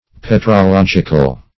Search Result for " petrological" : The Collaborative International Dictionary of English v.0.48: Petrologic \Pet`ro*log"ic\, Petrological \Pet`ro*log"ic*al\, a. Of or pertaining to petrology.